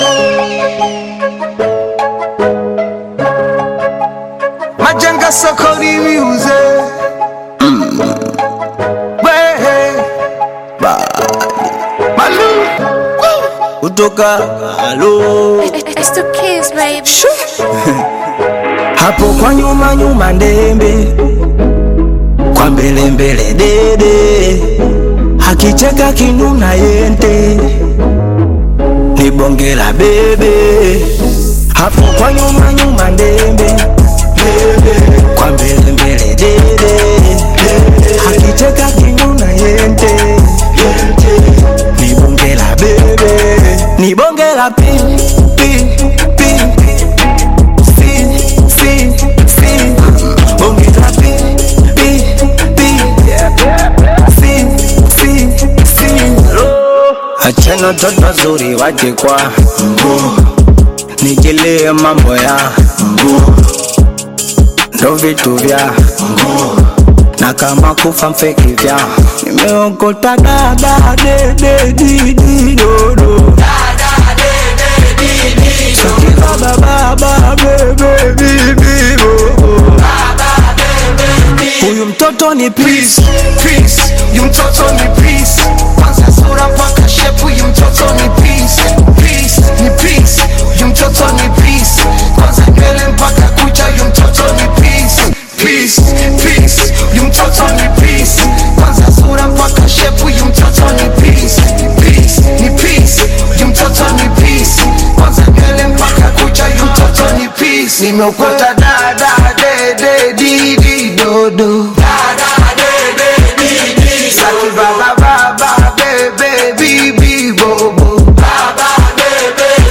Tanzanian Bongo Flava